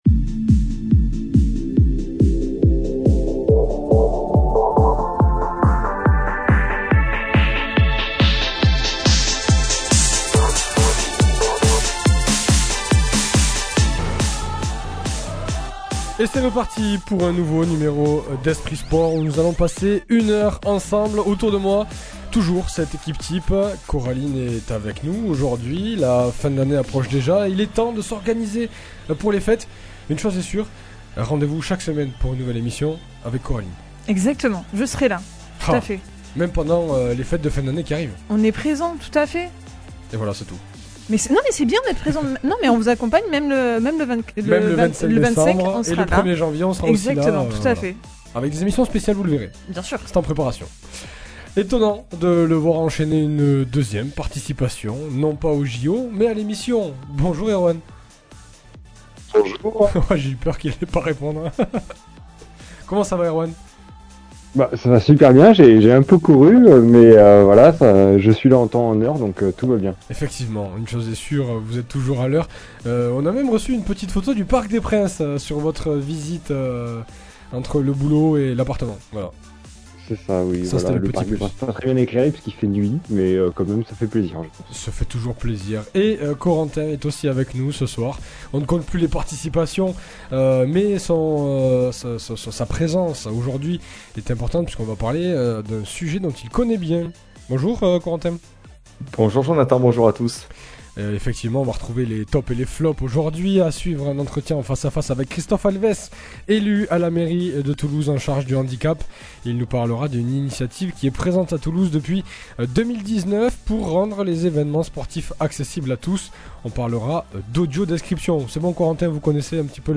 Un nouvel invité au micro d’Esprit Sport, Christophe Alvès, élu à la Mairie de Toulouse en charge du Handicap, de l’Occupation du Domaine Public, des Centres Sociaux et de la Vie Associative, sera dans cette émission. Il nous parlera d’une initiative que peu de ville en France connaissent, la mise en place de l’audiodescription des événements sportifs. L’objectif de la métropole Toulousaine : rendre ses rendez-vous accessibles à tous !